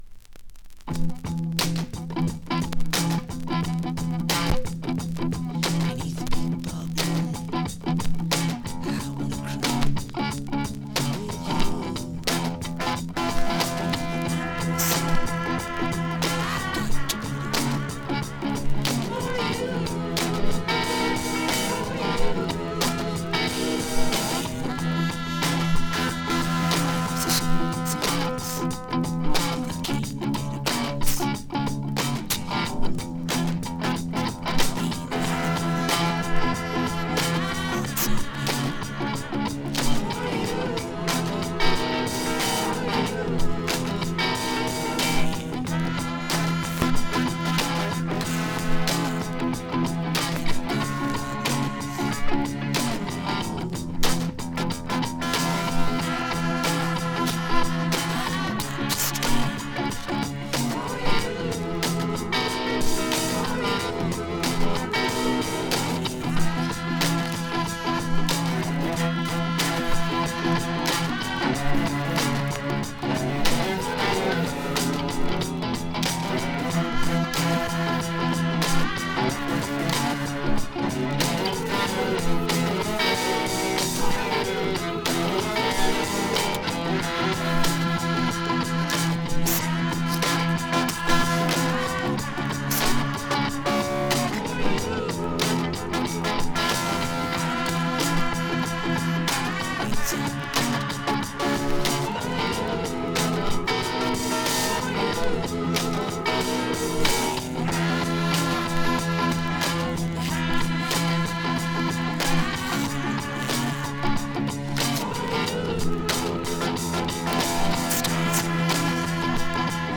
PROG